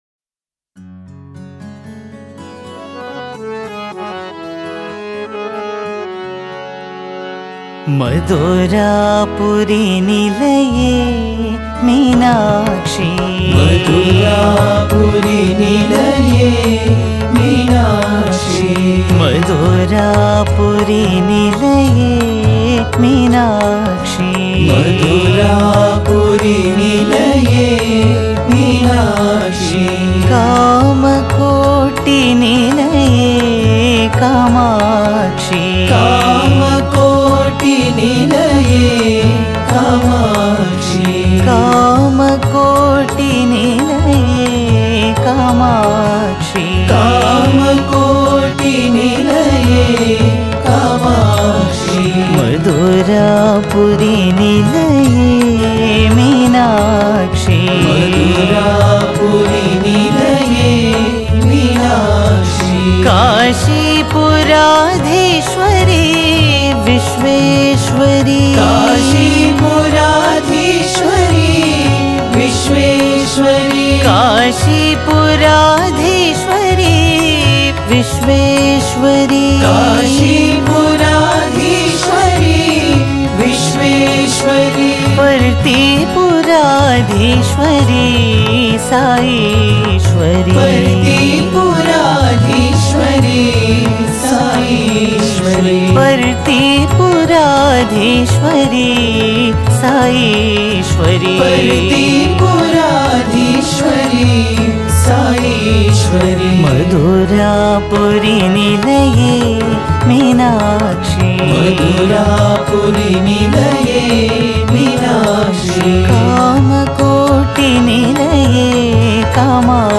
Home | Bhajan | Bhajans on various Deities | Devi Bhajans | 38 – Madurapuri Nilaye Meenakshi